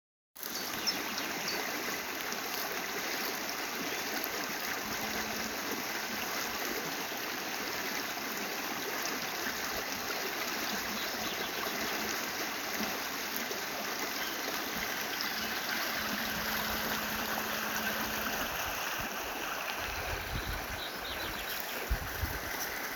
Nous rebroussons chemin et prenons un chemin à droite conduisant à une passerelle qui nous permet de traverser le ruisseau.
et chante le ruisseau